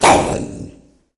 npc_draugr_aware_01.mp3